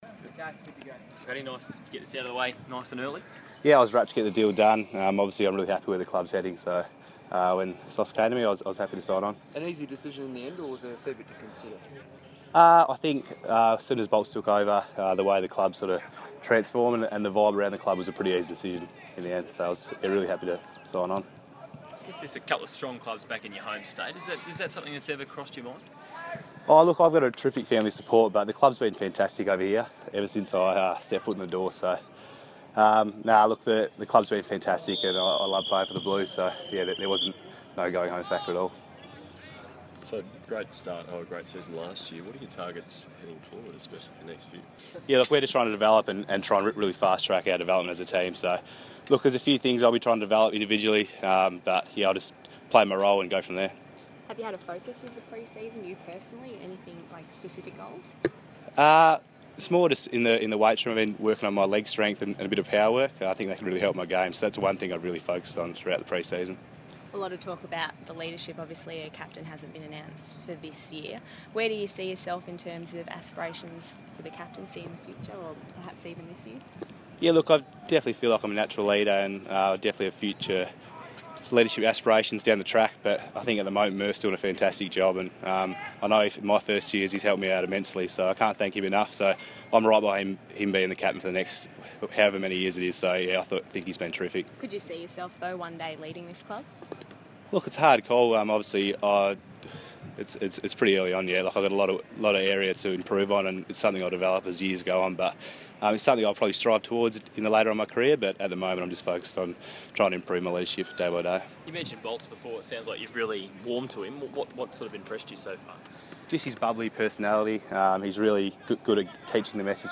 Patrick Cripps press conference - February 5